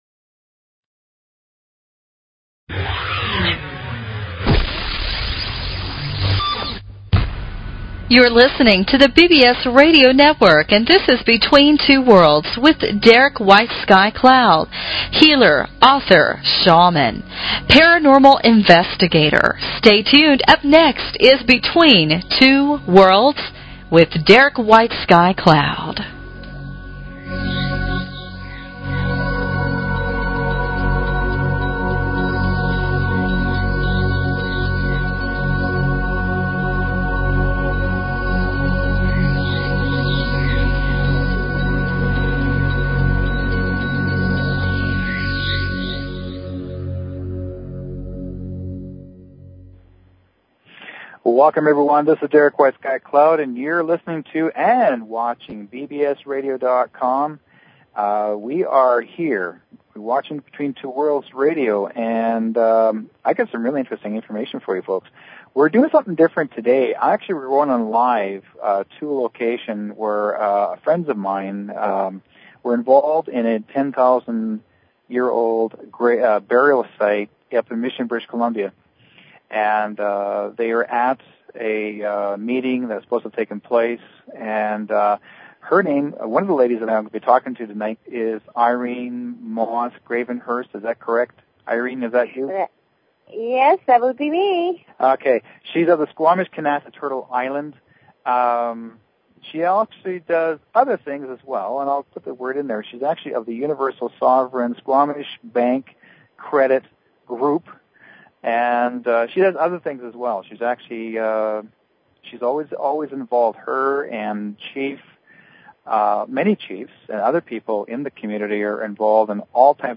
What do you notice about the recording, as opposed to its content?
We will be talking live on site in a meeting on the protection of a 10,000 yr old burial ground...that has been destined to be dug up by the Canadian provincial government for an industrial site.